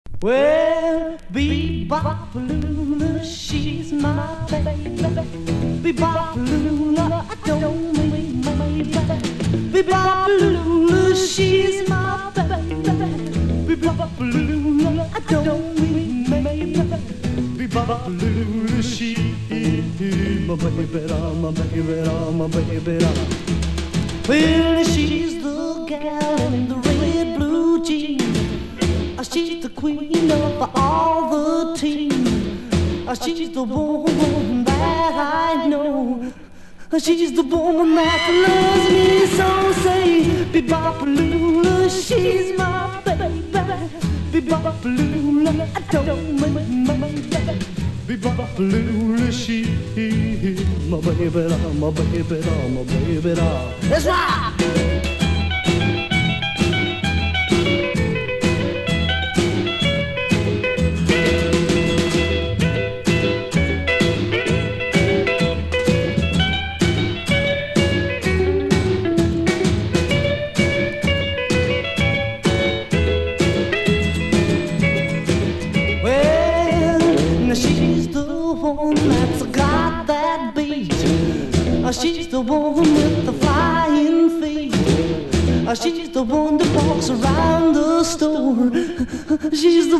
1. 60'S ROCK >
ROCKABILLY / SWING / JIVE